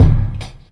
Kick 1.wav